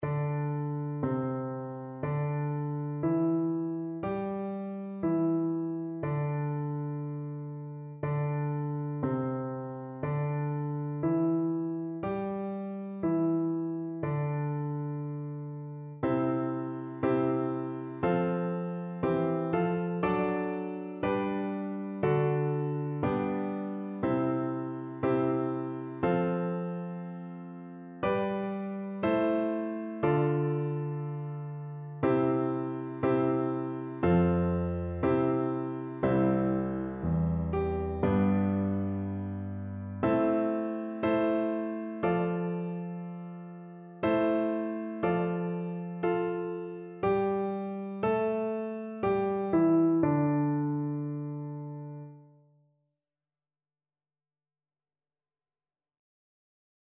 Traditional Trad. Kimigayo (Japanese National Anthem) Piano version
No parts available for this pieces as it is for solo piano.
C major (Sounding Pitch) (View more C major Music for Piano )
4/4 (View more 4/4 Music)
Slow
Traditional (View more Traditional Piano Music)